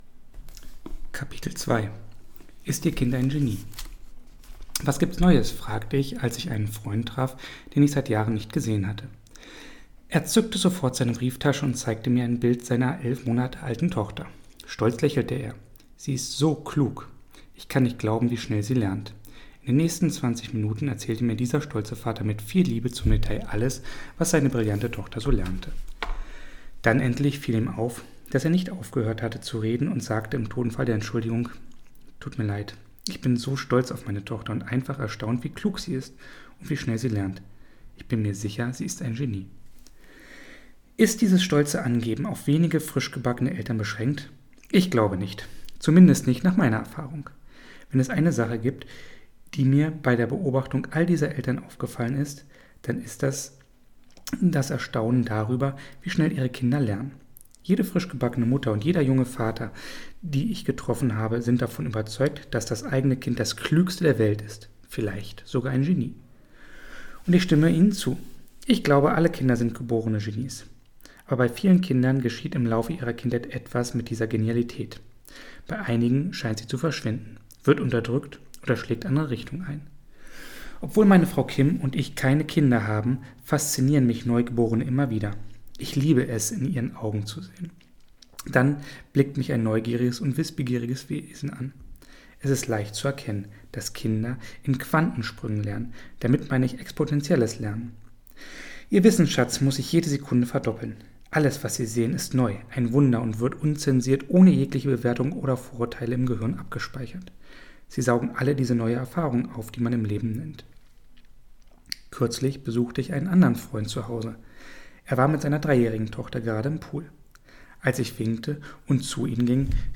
mit Liebe vorgelesen